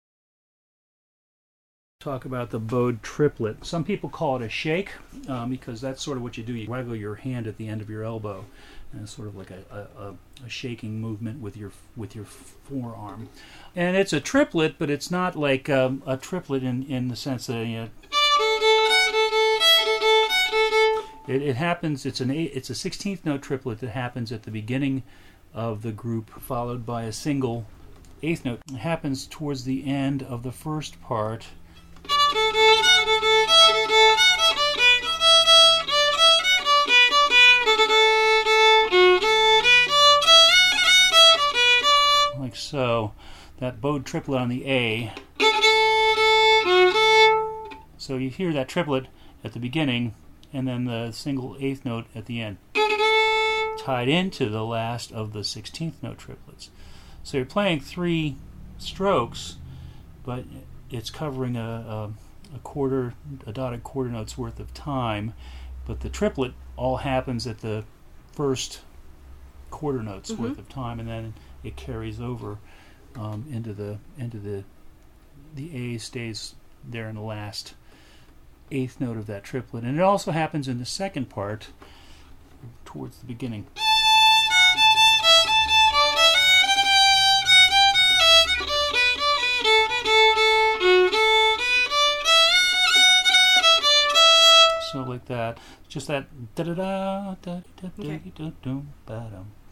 Title: The Shake or Bowed Triplet
Description: Another "Big" Sligo ornament.
I often set them up with a big down bow on the previous note and perform them starting on the up stroke.
Shakes.mp3